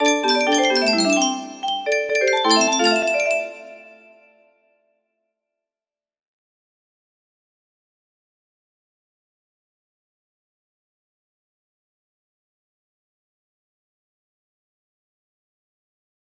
music box *remade* music box melody